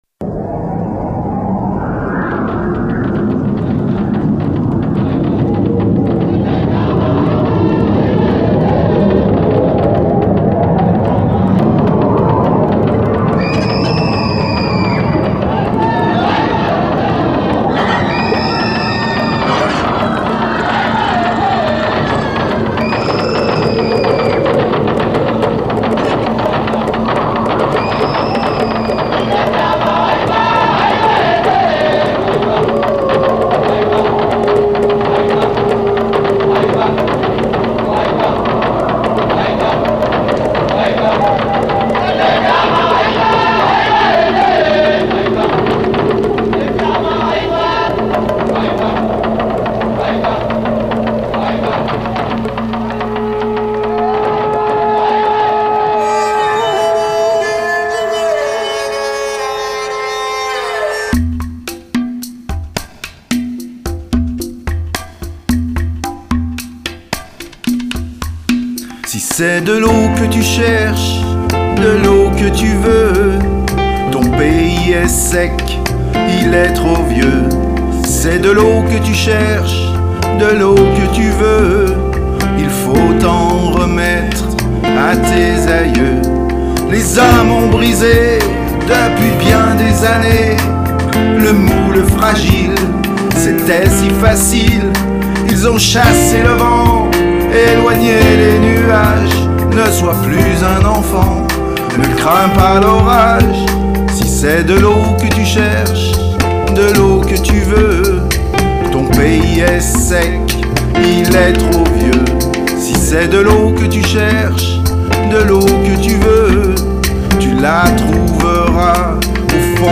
voix, claviers, guitares,basse et programmation batterie
La Fiction pop-rock